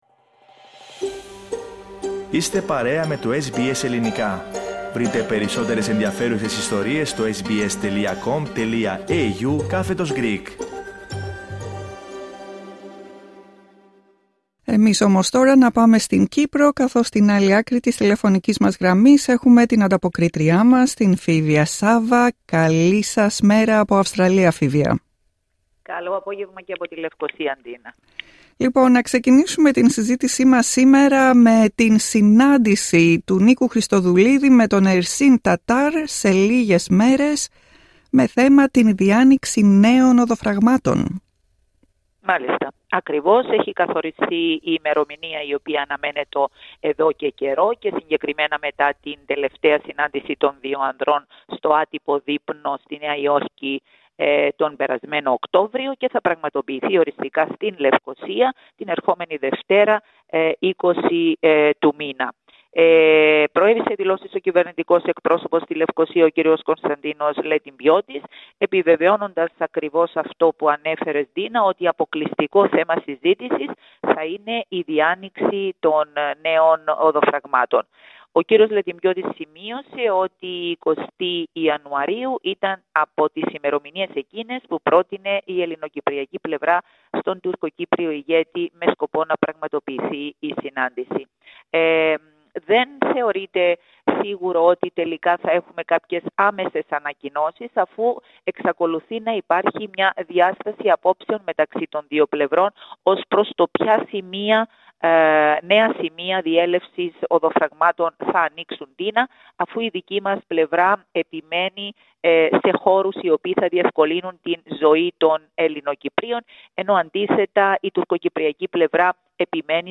" Ελπίζω και ο κύριος Τατάρ να έρθει με την ίδια διάθεση και να μπορούμε να ανακοινώσουμε αποτελέσματα που θα είναι προς όφελος του κυπριακού λαού στο σύνολό του” Ακούστε τα υπόλοιπα θέματα της ανταπόκρισης από την Κύπρο, πατώντας PLAY δίπλα από την κεντρική φωτογραφία.